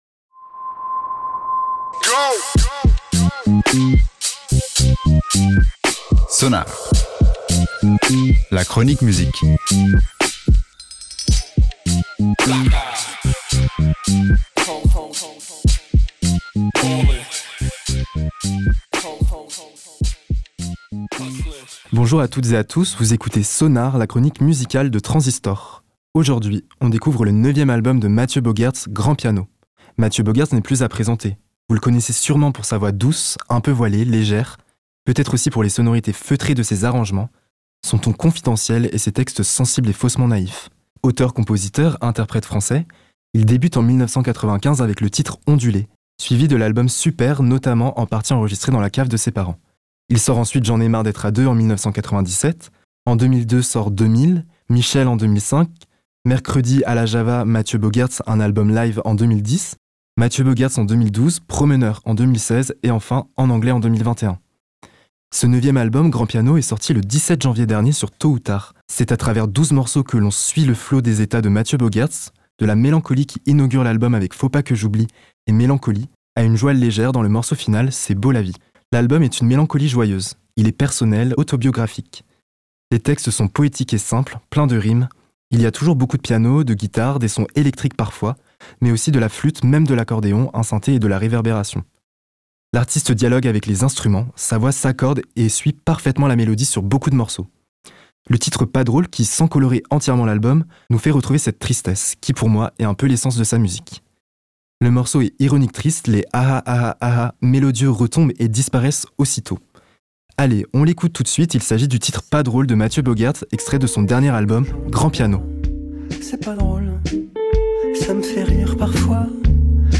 une tristesse familière